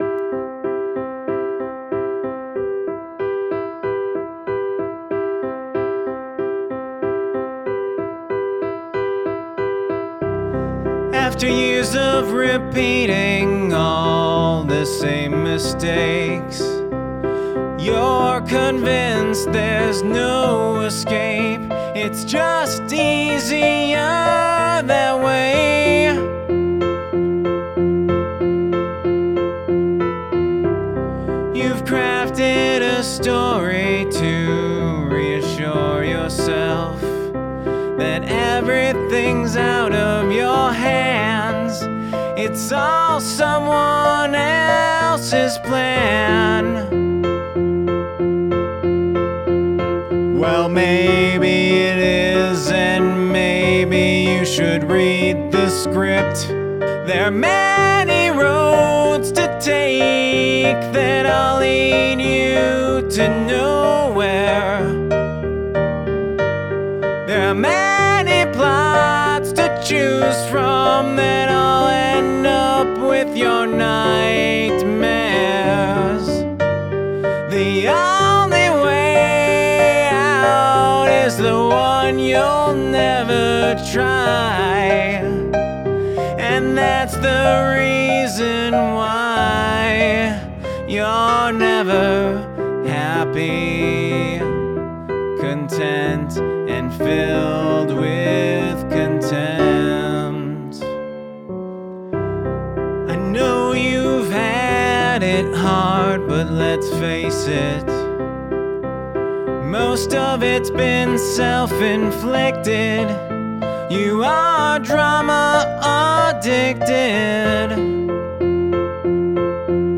Downward Modulation
Challenge: My verse is in C and my chorus is in Gm. The transition back to C involves Adim -> D7 -> C. Which is downward.
I felt the keys sounded good but were a bit static and needed more dynamics.
The arrangement of piano, bass guitar, and voice is certainly valid.
Your vocal need some work as well, as we can hear you searching for the right note in a few places.